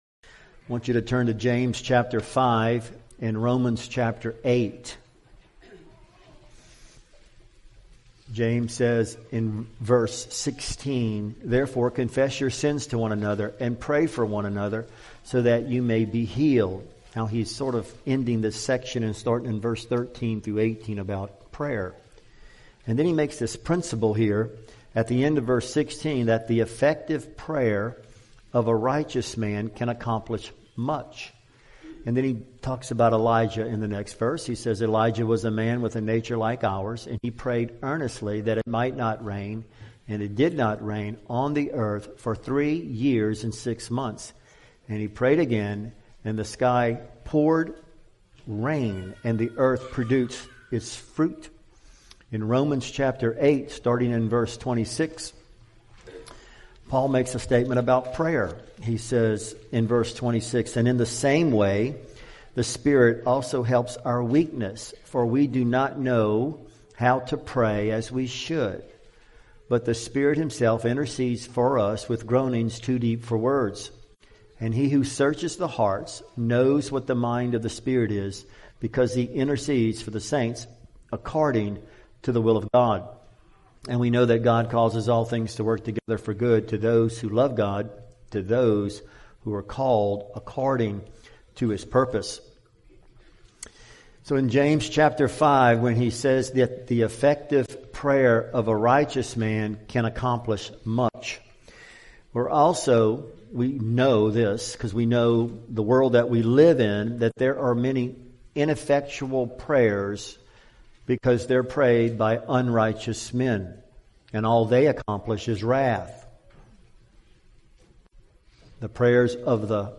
Category: Full Sermons